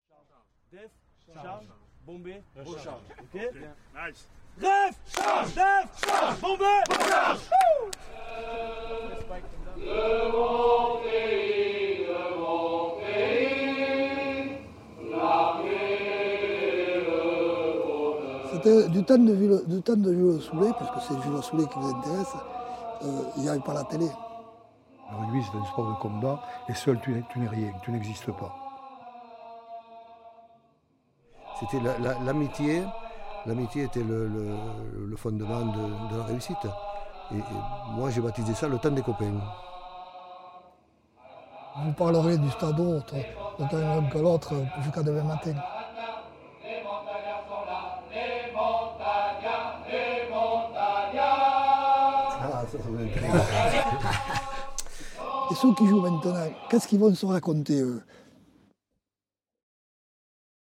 INA « Le quinze de France chante « Montagnes Pyrénées » » 1964